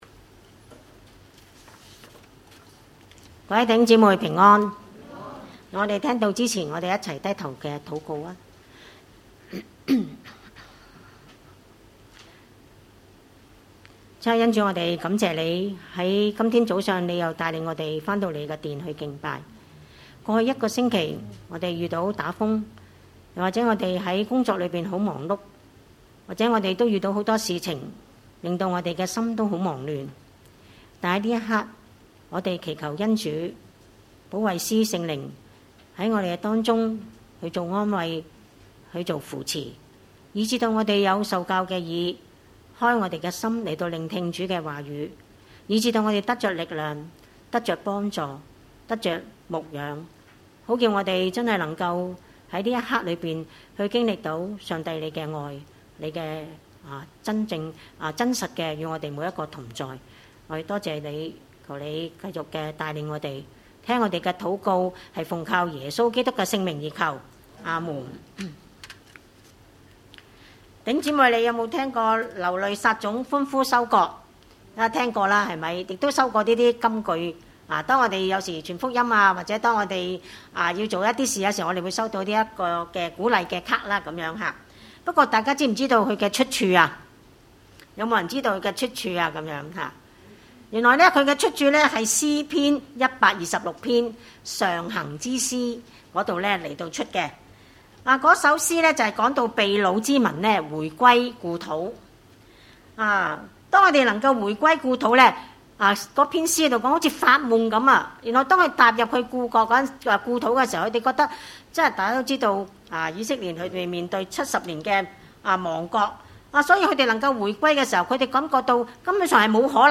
經文: 約翰福音4：27-42 崇拜類別: 主日午堂崇拜 27 當 下 門 徒 回 來 、 就 希 奇 耶 穌 和 一 個 婦 人 說 話 ． 只 是 沒 有 人 說 、 你 是 要 甚 麼 ． 或 說 、 你 為 甚 麼 和 他 說 話 。